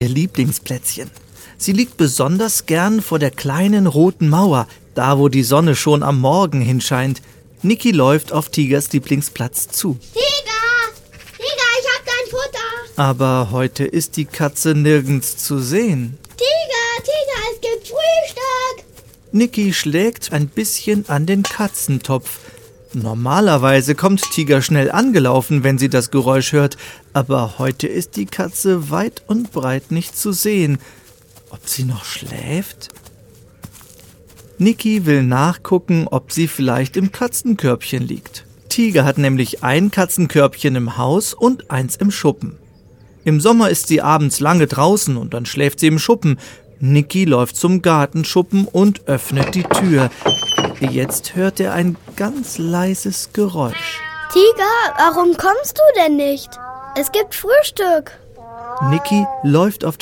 (Hörbuch/Hörspiel - CD)
Hörspiele